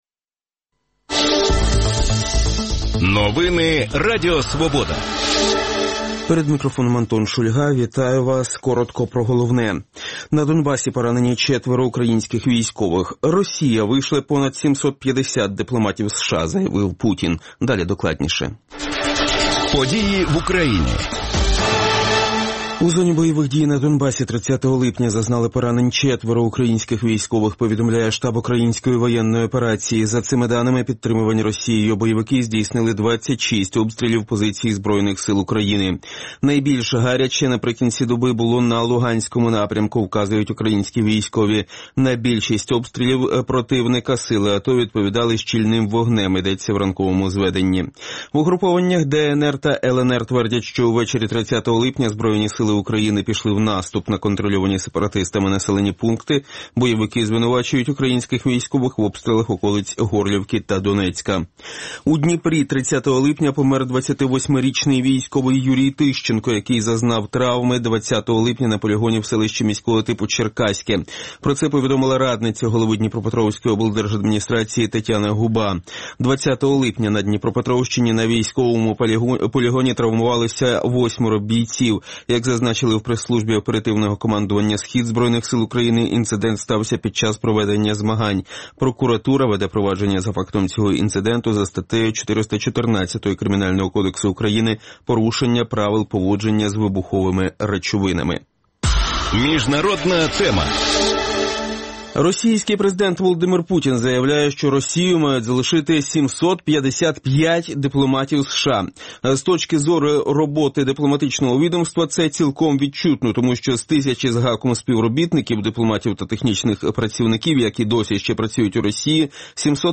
говоритиме з гостями студії